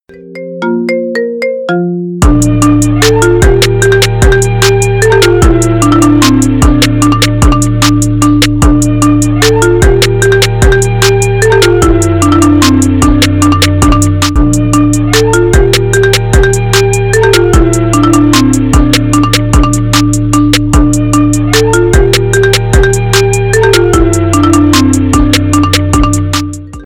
• Качество: 320, Stereo
спокойные
без слов
Bass